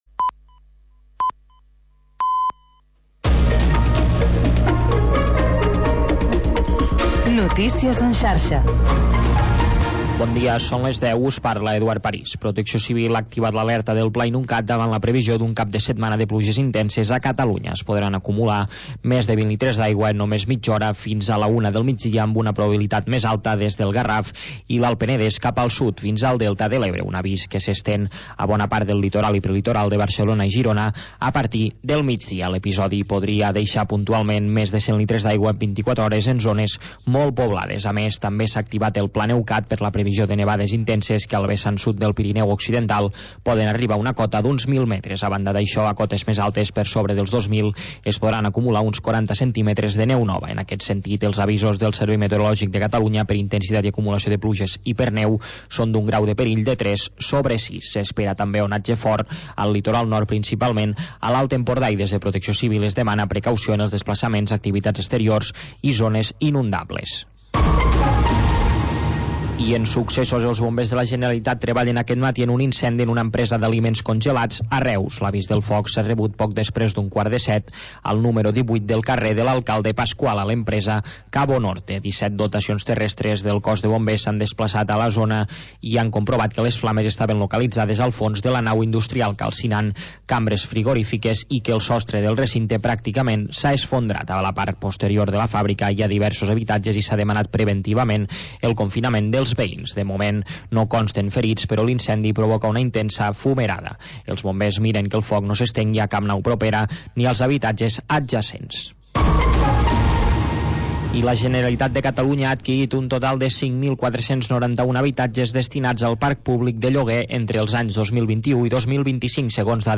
Programa sardanista, amb actualitat, compositors i agenda de ballades